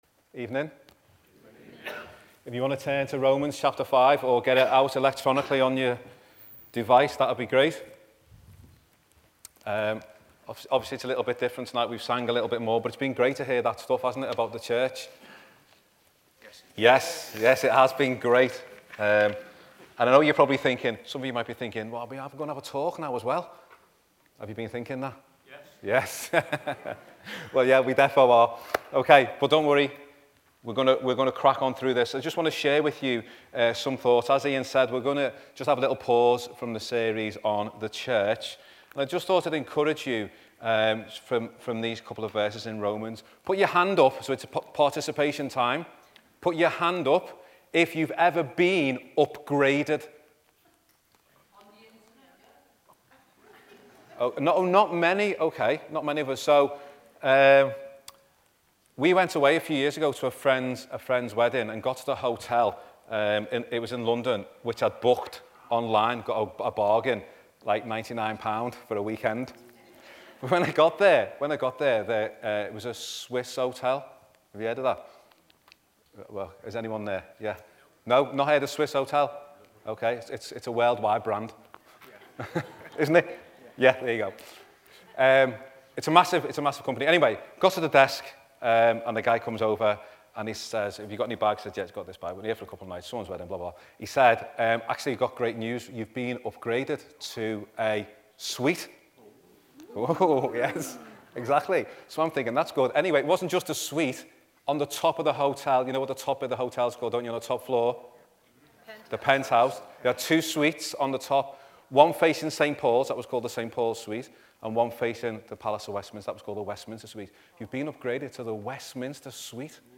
THE CHURCH 5. ITS NEW STATUS (Romans 5:1-2) – Welcome To Bridge Chapel Liverpool Sermon Archive